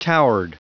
Prononciation du mot towered en anglais (fichier audio)
Prononciation du mot : towered